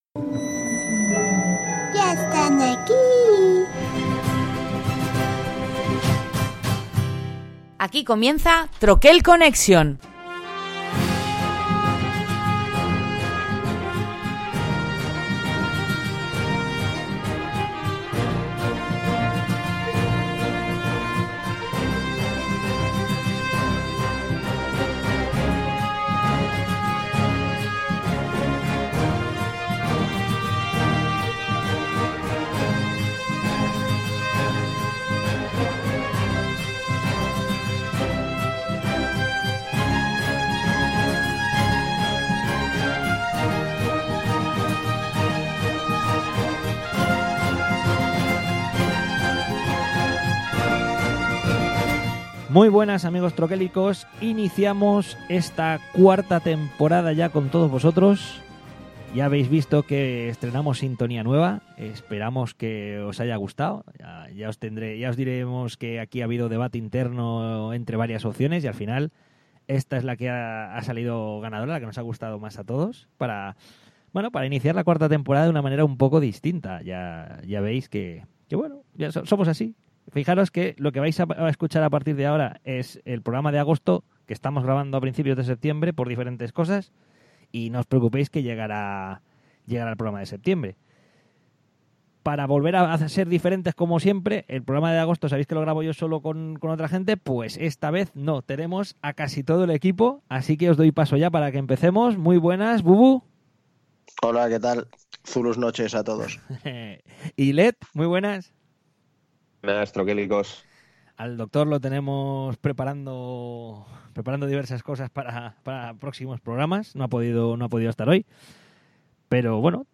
Más distendido que de constumbre, y estrenando nueva sintonía, damos un pequeño gran repaso a tres juegos que han destacado en nuestras mesas durante las vacaciones. Así iniciamos nueva temporada que esta cargada como siempre de muchos juegos con nuestro habitual tono de irreverencia.